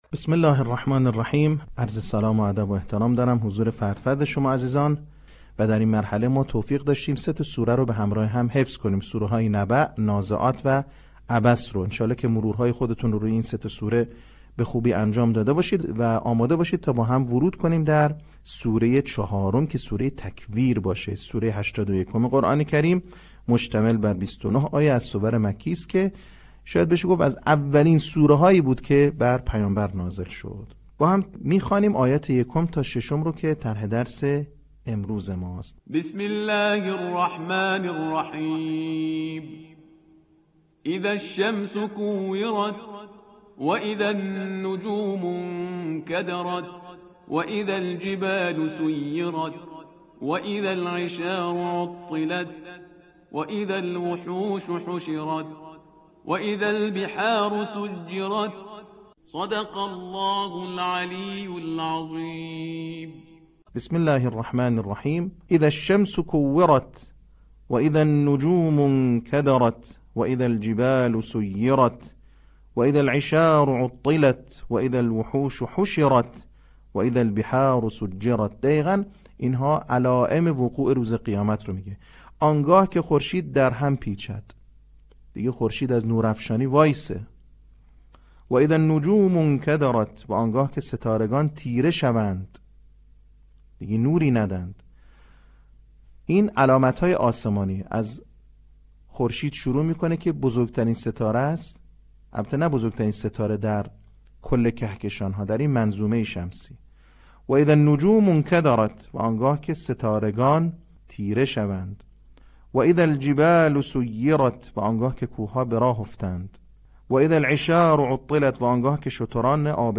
صوت | آموزش حفظ سوره تکویر